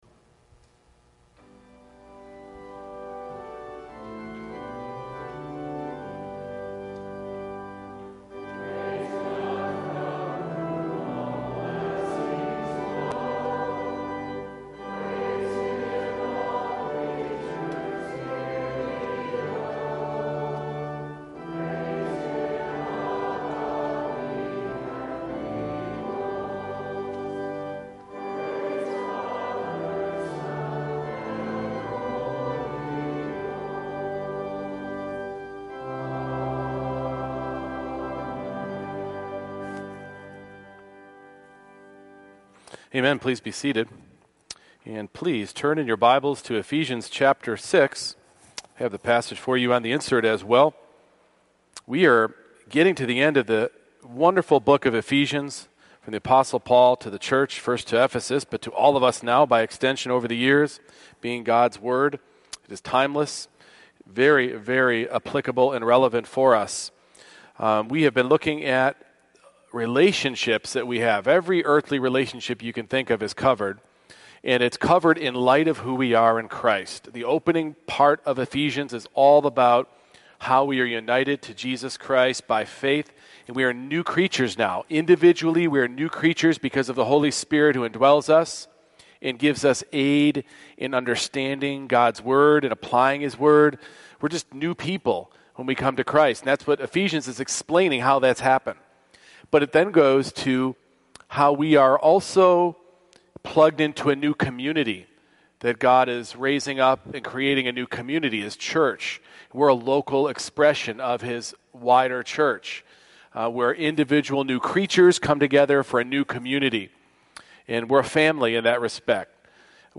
Ephesians 6:5-9 Service Type: Morning Worship The Lordship of Christ extends to every aspect of life